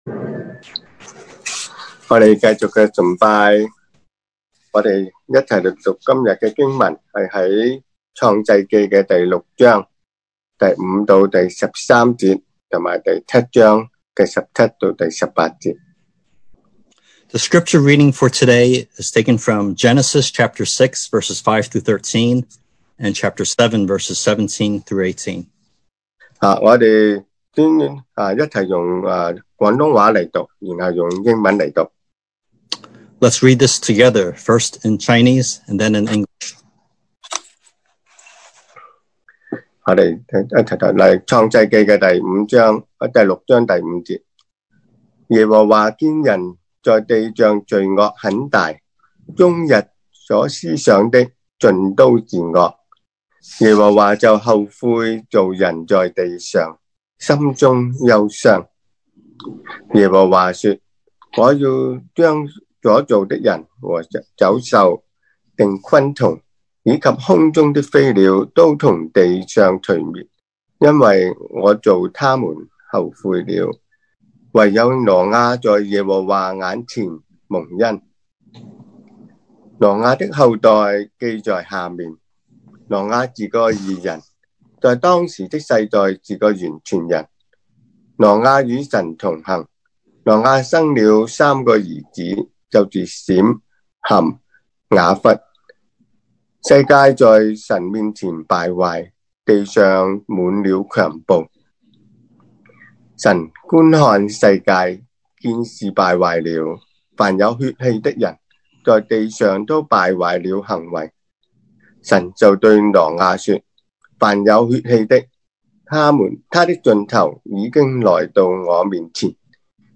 2020 sermon audios
Passage: Genesis 6:5-13, Genesis 7:17-18 Service Type: Sunday Morning